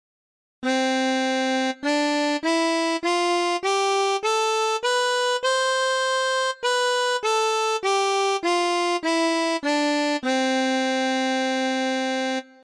シンセで学ぶ楽器と音色【ＧＭ音源】
023：ハーモニカ（Harmonica）
ＧＭ音源プログラムチェンジの２３番は、ハーモニカ（Harmonica）の音色です。
ハーモニカは、金属製のリードに風を送り込み発音させる吹奏楽器です。